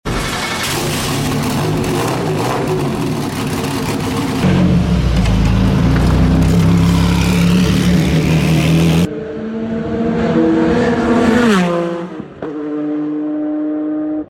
Volume Up 🔊 here’s the raw sound of a Porsche GT1 & flames 🔥